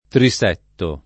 trisetto
trisetto [ tri S$ tto ]